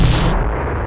Snare (RoboCop).wav